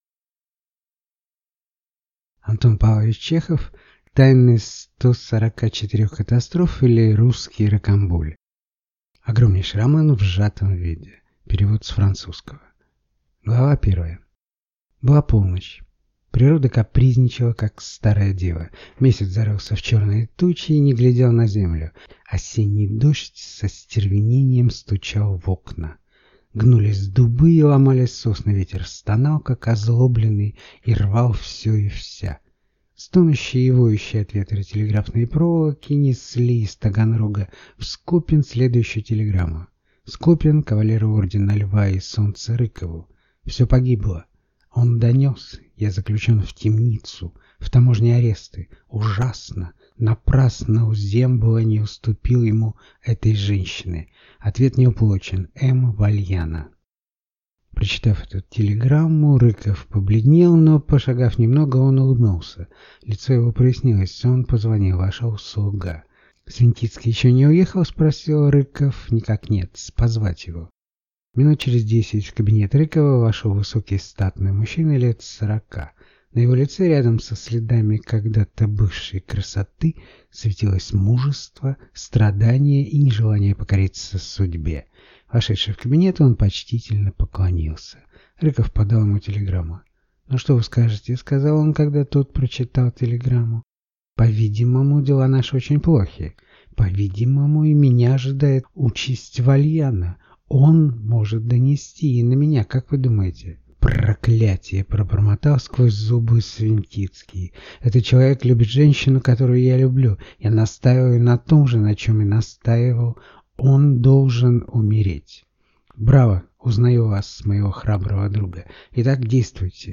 Аудиокнига Тайны 144 катастроф, или Русский Рокамболь | Библиотека аудиокниг